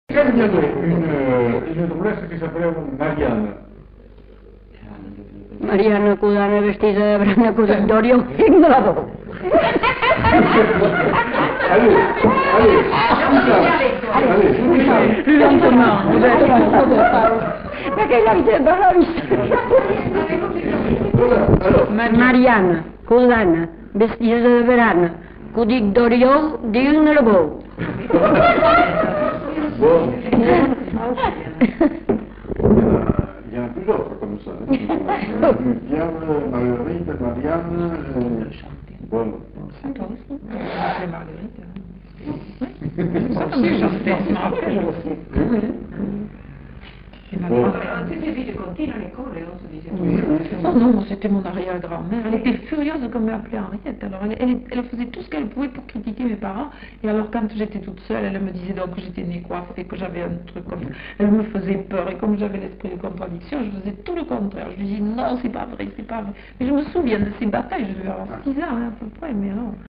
Lieu : Villandraut
Effectif : 1
Type de voix : voix de femme
Production du son : récité
Classification : formulette enfantine